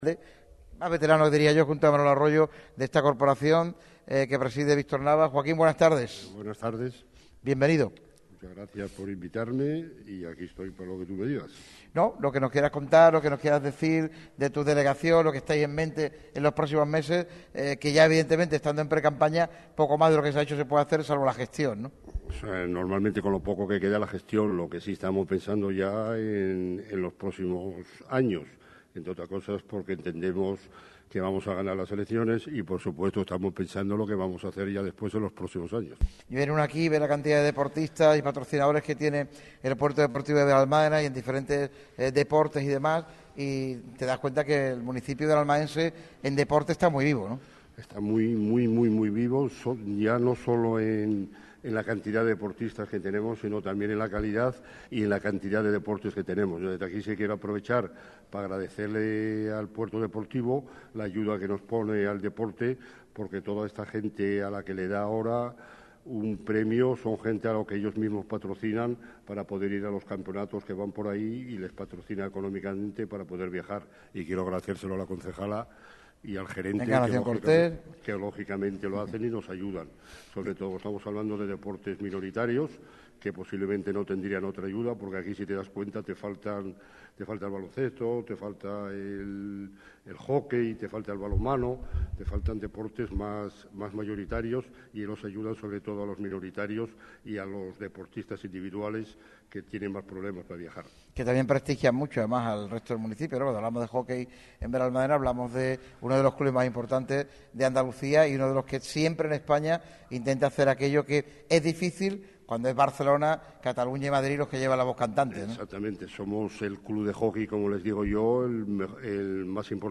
Con motivo del programa especial en el Puerto Deportivo de Benalmádena, Radio MARCA Málaga quiso entregar la insignia de oro a Joaquín Villazón, concejal de Deportes de la localidad.